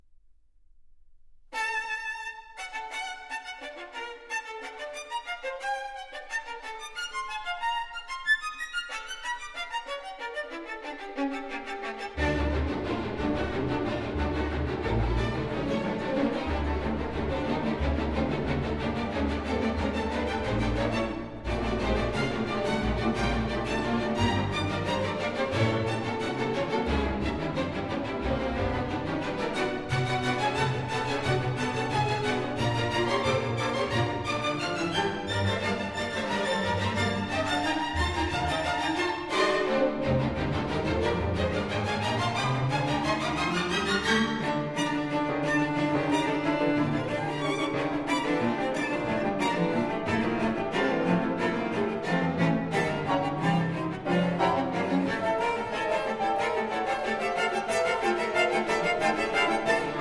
Recording : April 2004 at Studio Stolberger – Köln (Germany)